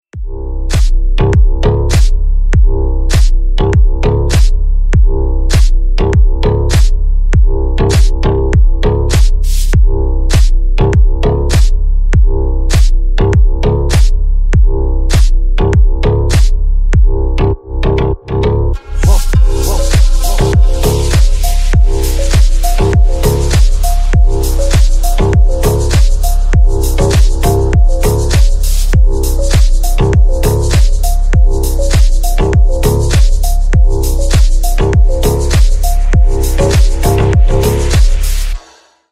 Электроника
клубные # без слов